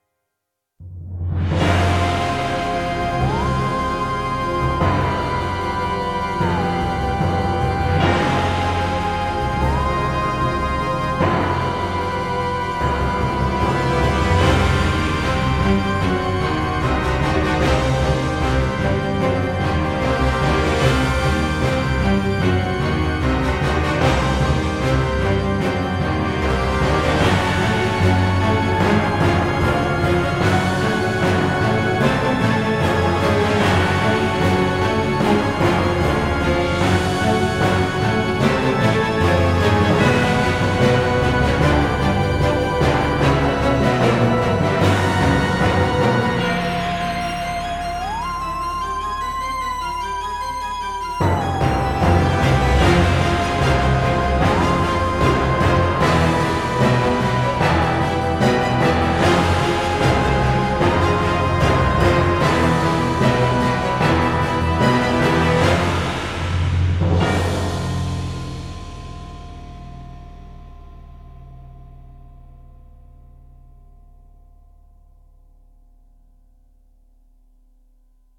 Genre: filmscore.